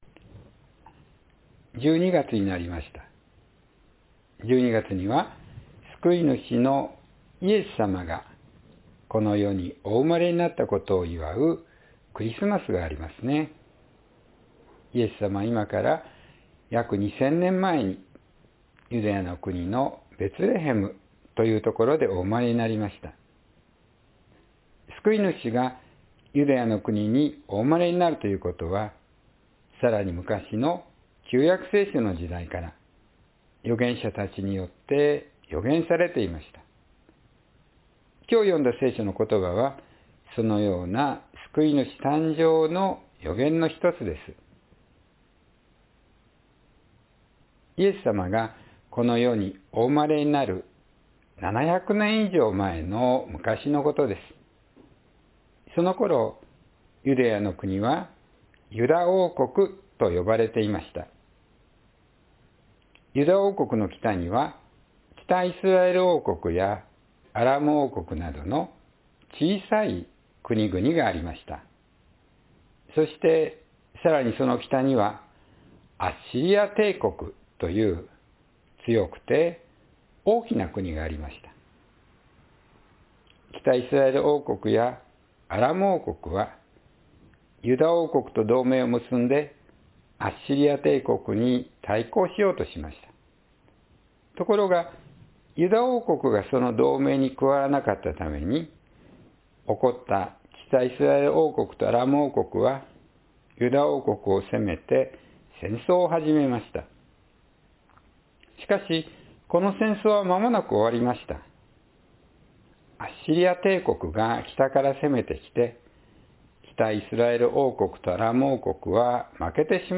平和の王の誕生“The Birth of the King of Peace”（2024年12月1日・子ども説教） – 日本キリスト教会 志木北教会